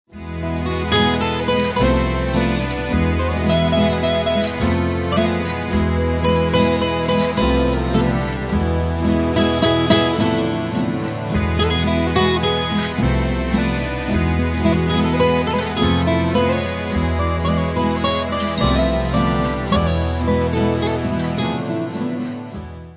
Download un'anteprima di bassa qualità